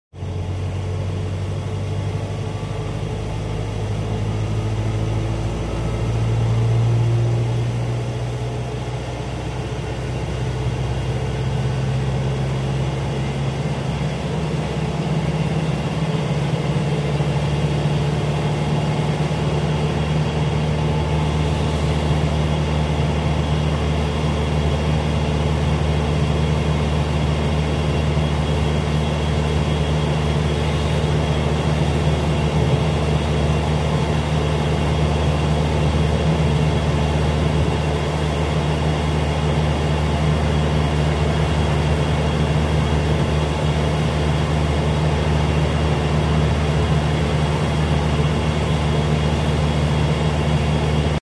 80371 K99 NHS attempting to whip it down A40 But she's limited to 45 So she doesn't get very far also cooling fans roaring